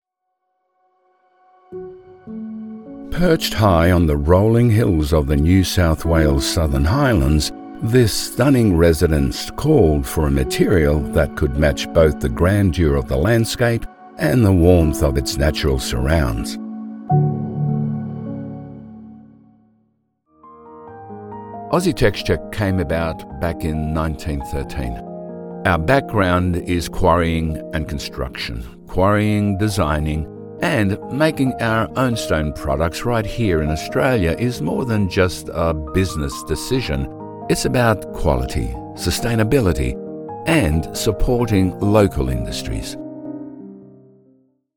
Male
English (Australian)
Older Sound (50+)
Words that describe my voice are Warm, Corporate, Authoritative.